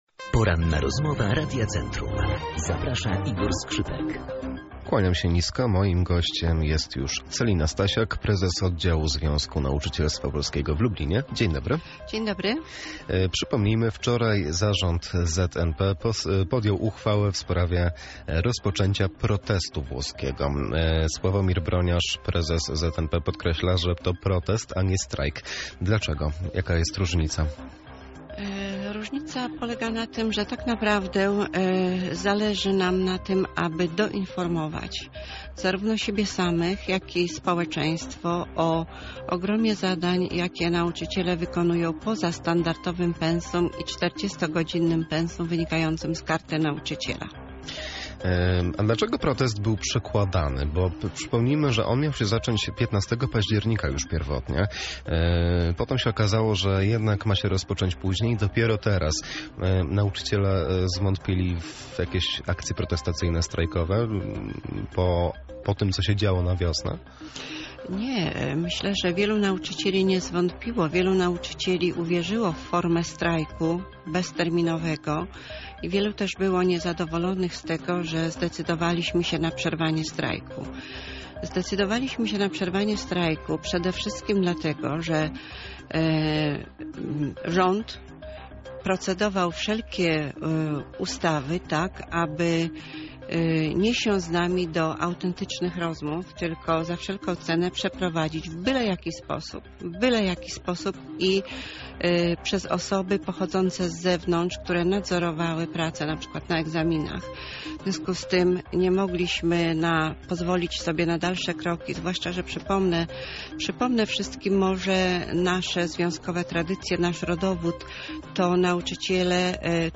Pełna rozmowa dostępna poniżej: